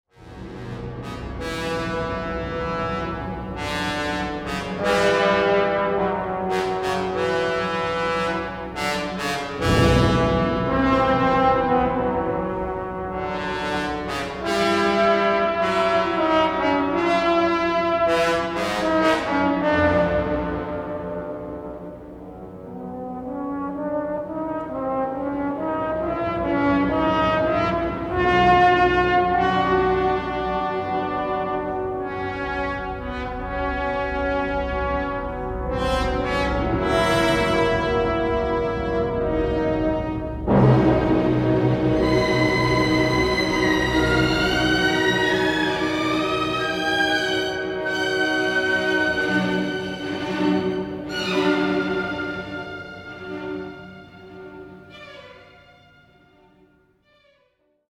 Niestety jakość dźwięku nie jest najlepsza, przez to wartko prowadzony temat pierwszy nie brzmi zbyt pewnie czy energicznie.
Tak brzmią niektóre z wejść blachy. Zwróćcie uwagę na charakterystyczny, rozwibrowany dźwięk: